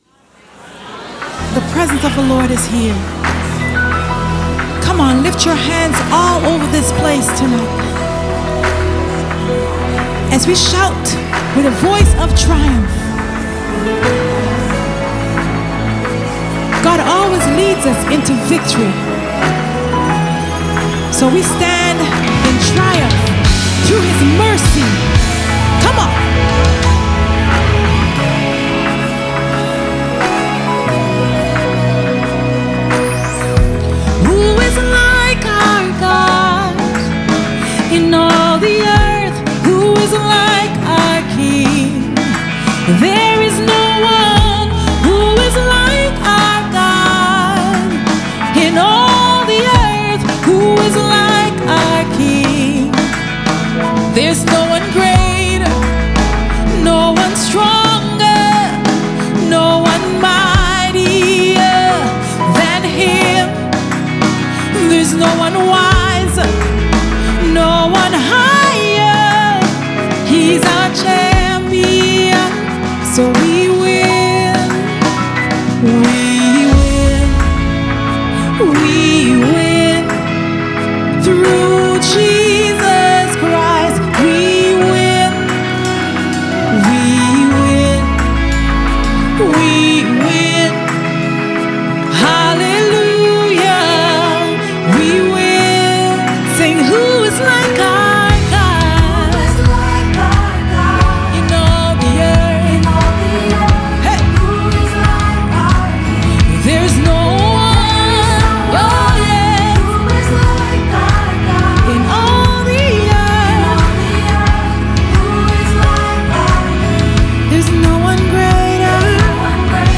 prophetic Anthem of victory
Gospel